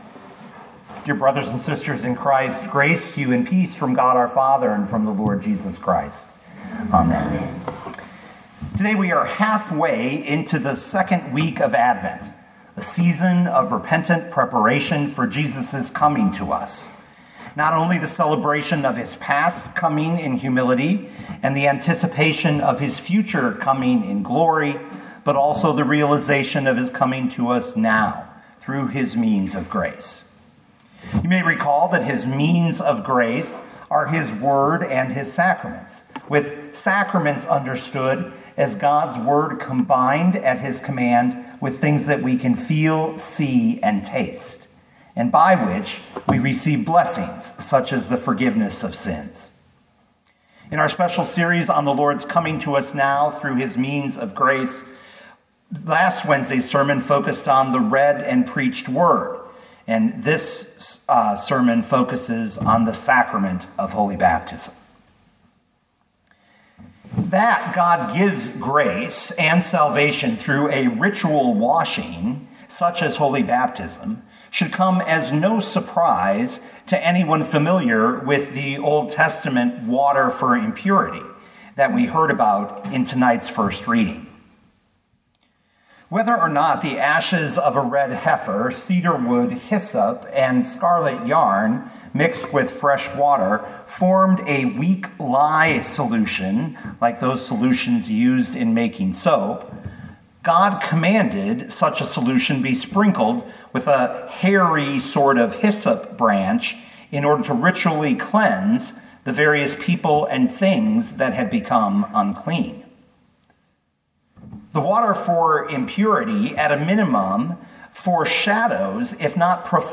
Matthew 28:18-20 Listen to the sermon with the player below, or, download the audio.